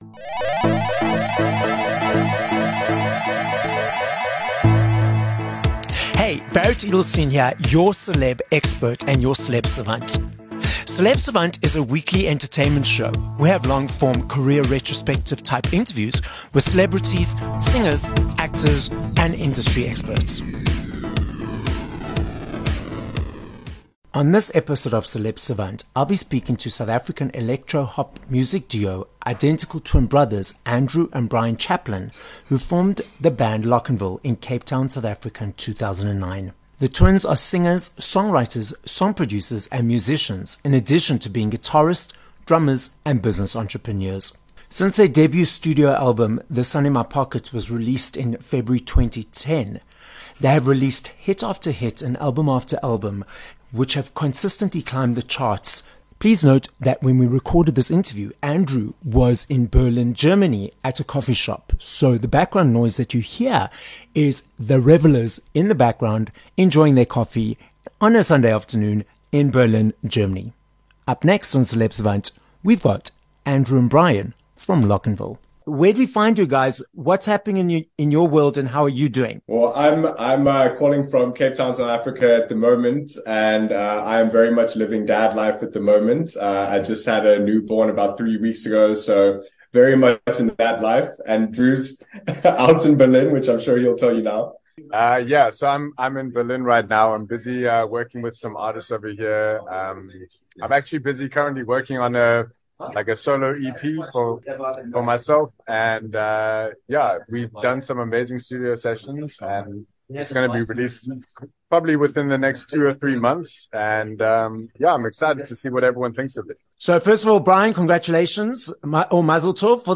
15 Mar Interview